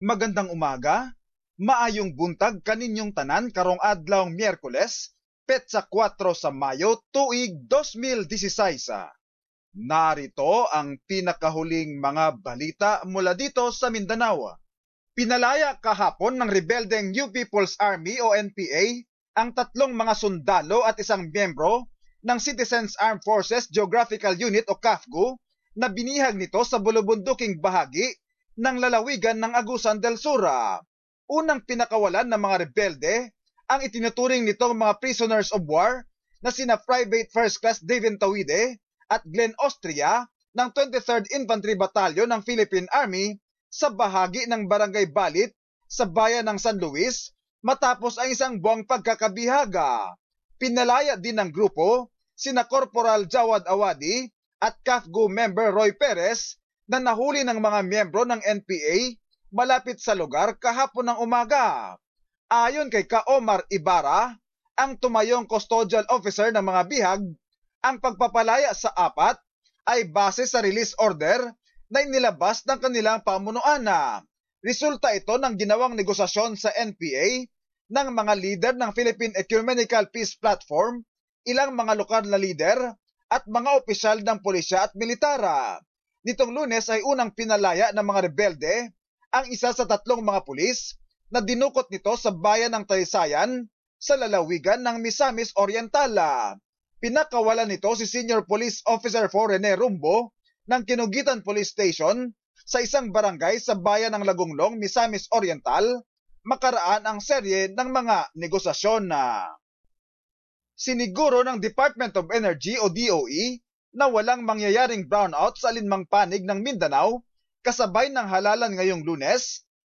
Mindanao News. Summary of latest news from Mindanao